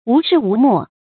無適無莫 注音： ㄨˊ ㄕㄧˋ ㄨˊ ㄇㄛˋ 讀音讀法： 意思解釋： 指無可無不可。